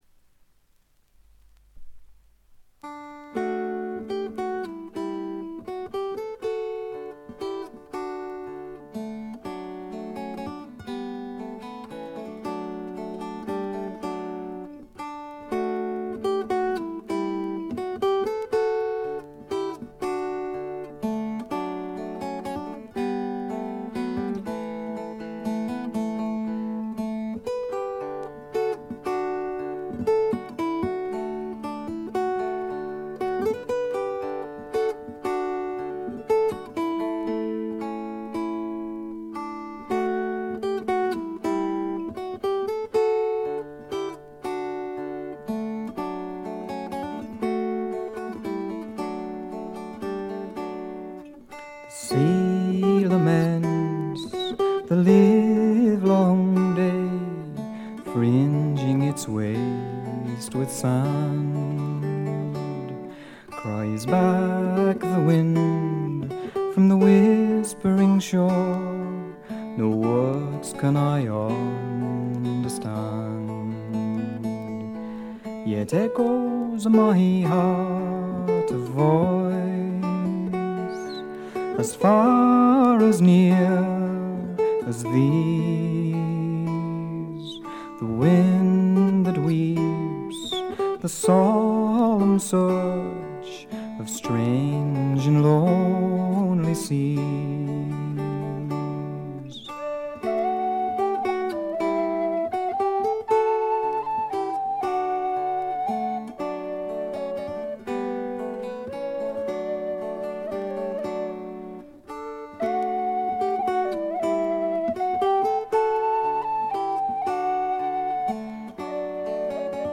微細なチリプチわずか。
自身のギター、ダルシマー、ツィターの他はコンサーティナ兼笛の奏者が付くだけの極めてシンプルな演奏を聴かせます。
まるで静寂そのものを聴かせるような、静謐で至上の美しさをたたえた作品です。
試聴曲は現品からの取り込み音源です。
Recorded At - Tonstudio St. Blasien